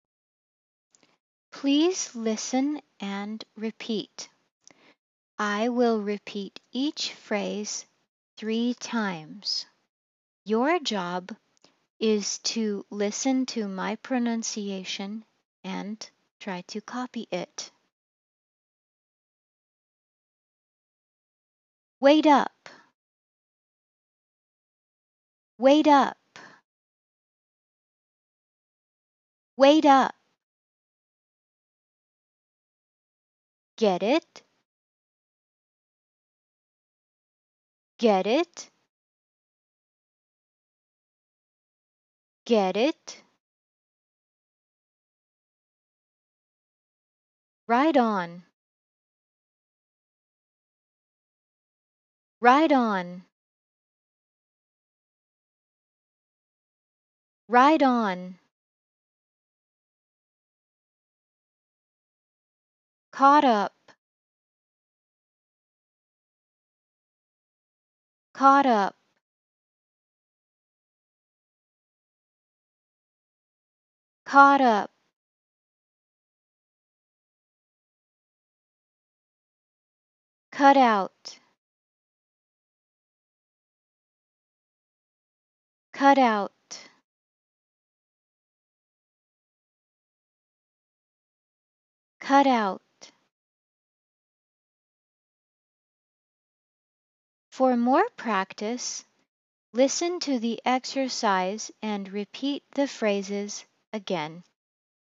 In the exercise, I will repeat each phrase 3 times with the flapping T. Your job is to repeat after me and try to copy my pronunciation. do not worry if you do not understand some of the phrases.